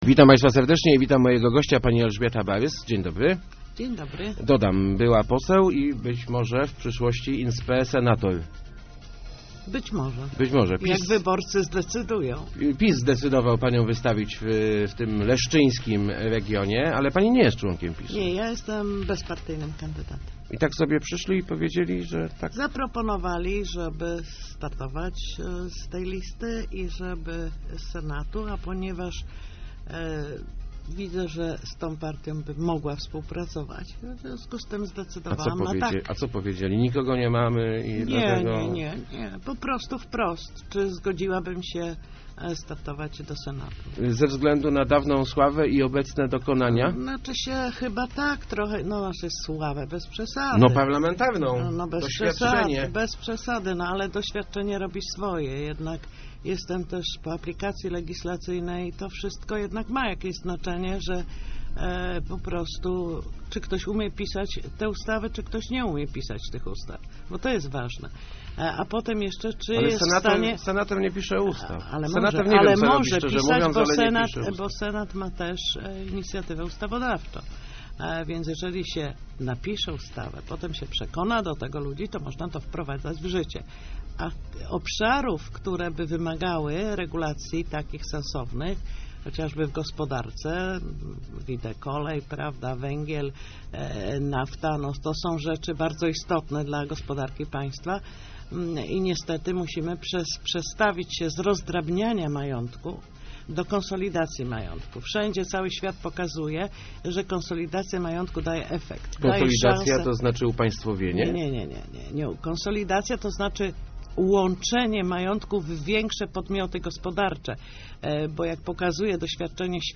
Kontrola wypływu pieniędzy z Polski jest najważniejszym sposobem na kryzys - mówiła w Rozmowach Elki Elżbieta Barys, kandydatka PiS do Senatu. Była posłanka postuluje też konsolidację krajowych firm, co da im możliwość konkurowania na rynku.